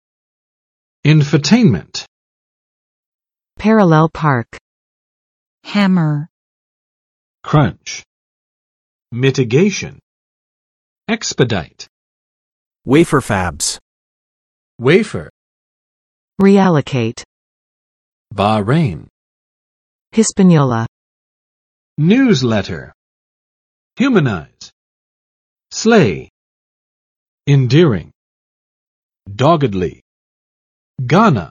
[͵ɪnfəˋtenmənt] n. 新闻娱乐化；信息娱乐化